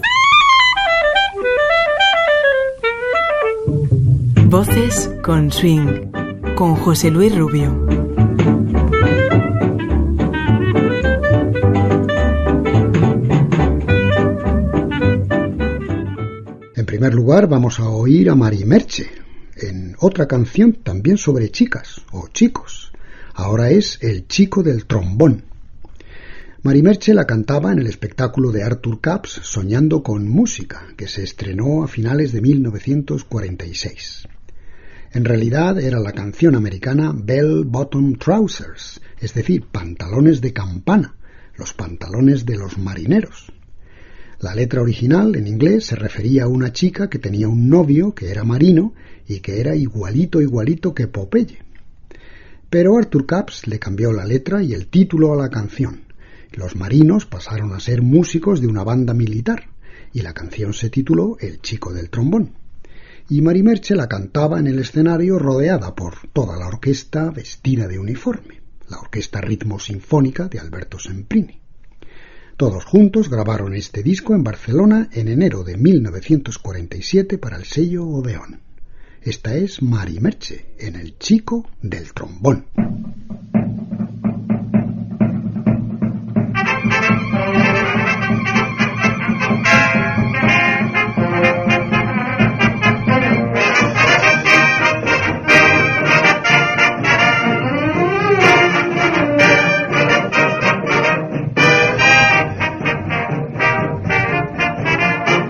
Careta del programa, presentació d'un tema enregistrat l'any 1947 a Barcelona.
Musical